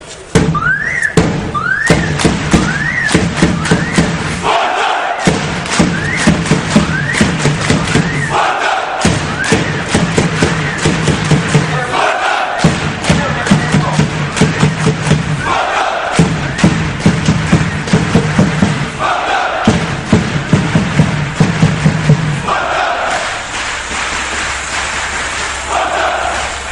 Заряды футбольных команд
Начало_шествия_фанатов_Спартака.mp3